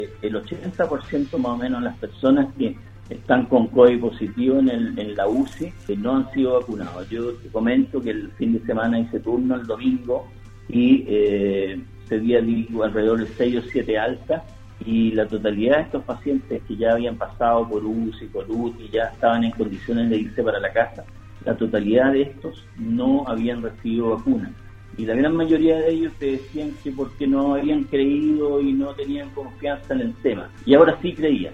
En conversación con Radio Sago